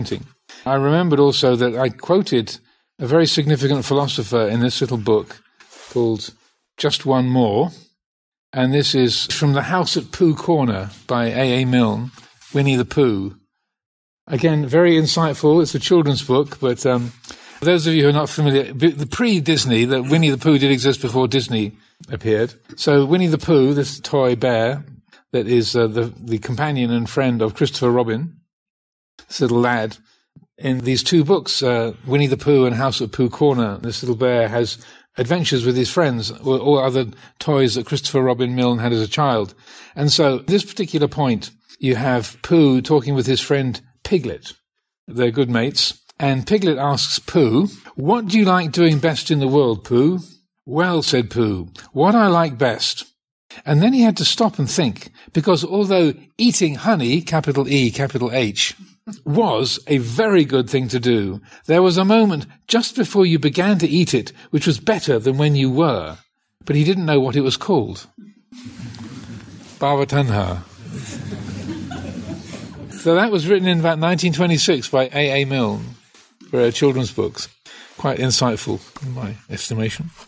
Reading